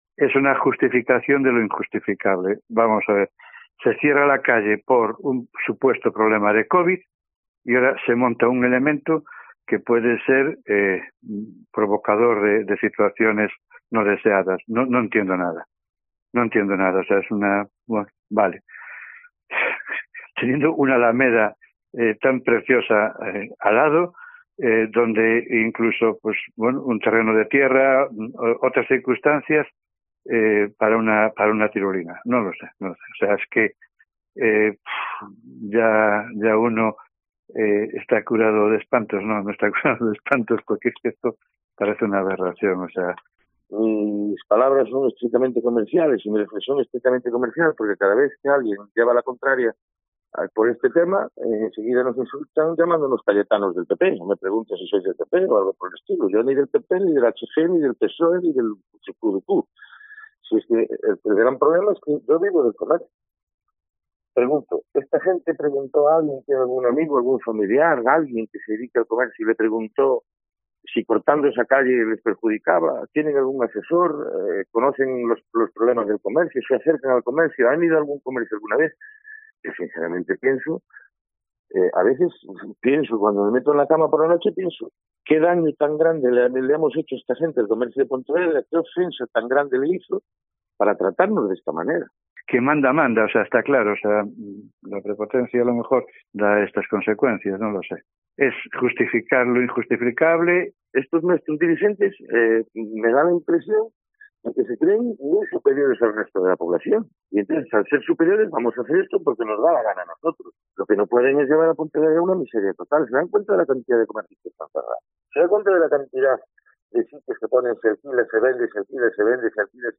Reacciones de empresarios de Pontevedra al anuncio de instalar una tirolina en Reina Victoria